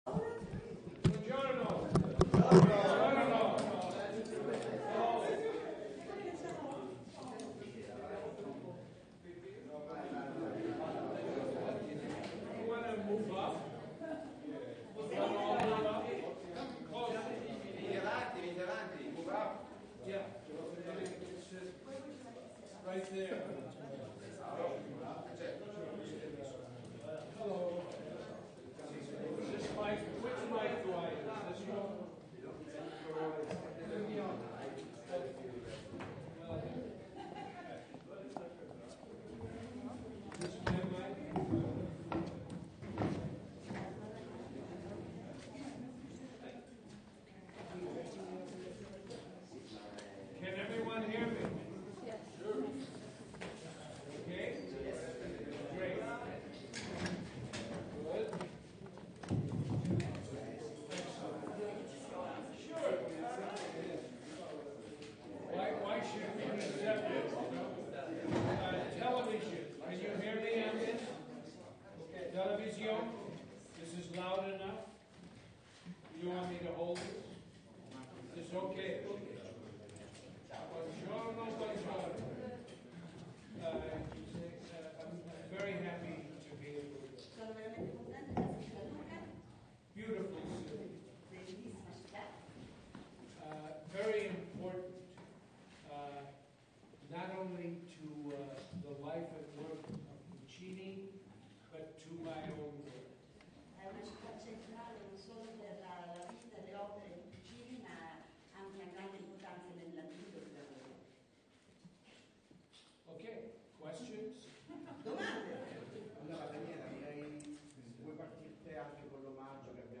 Conferenza stampa William Friedkin al Lucca Film Festival
Sabato 2 aprile in occasione della nuova edizione del Lucca Film Festival si è tenuta la conferenza stampa del regista William Friedkin
Con questo articolo potete ascoltare la piacevole e stimolante chiacchierata che noi giornalisti abbiamo avuto con William Friedkin, il regista de L'esorcista.
Nell’arco di un’ora il regista si è dimostrato alla mano, ironico e terribilmente lucido e disincantato.